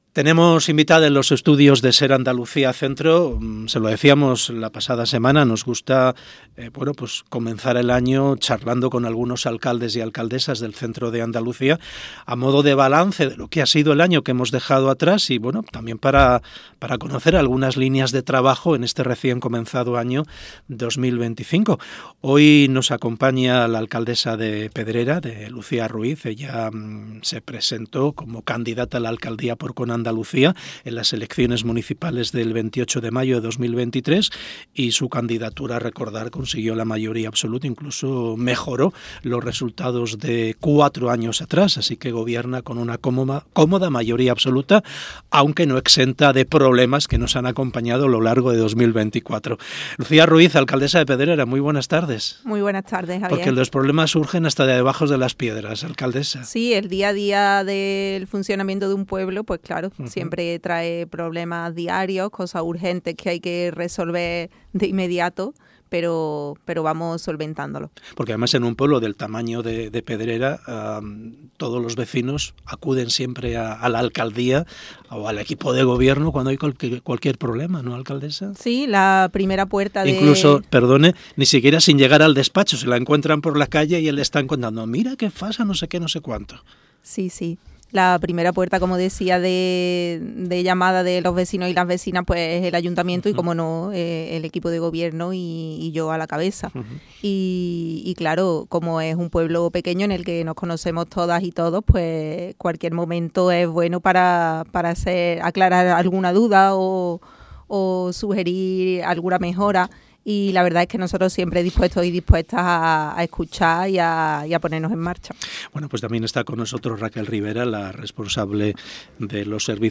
Entrevista Lucía Ruíz Alcaldesa de Pedrera - Andalucía Centro